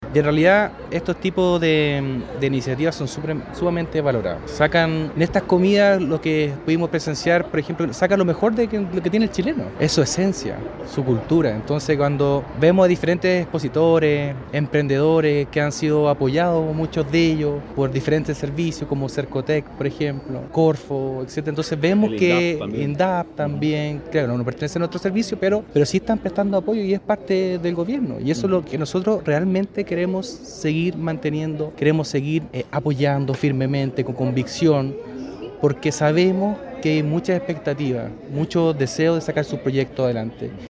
En el acto estuvo presente además el nuevo Seremi de Economía Fomento y Turismo de la región, Juan Carlos Meléndez, quien resaltó la importancia de este tipo de actividades en apoyo a los emprendedores regionales.